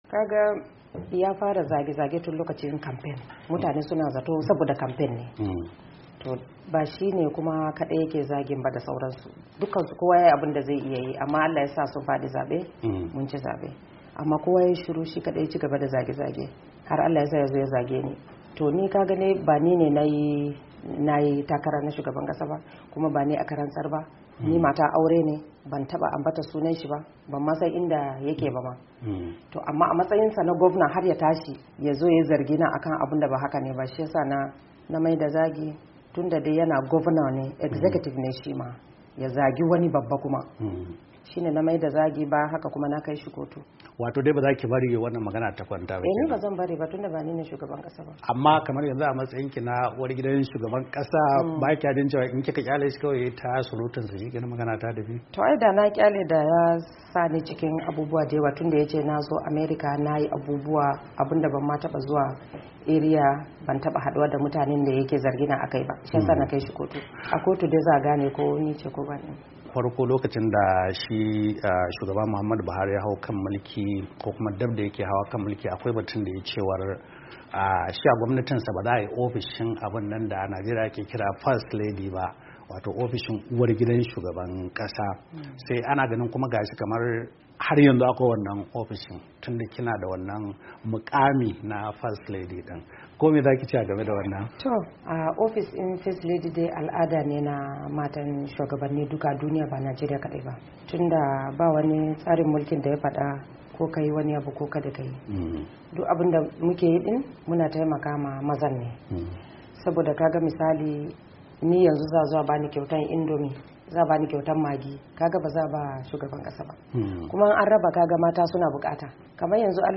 Ga tattaunawarsu nan a makalar sautin kasa.